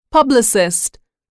단어번호.0670 대단원 : 3 소단원 : a Chapter : 03a 직업과 사회(Work and Society)-Professions(직업) 출제년도 : 13.17 publicist [pΛblisist] 명)선동자, 홍보 담당자 mp3 파일 다운로드 (플레이어바 오른쪽 아이콘( ) 클릭하세요.)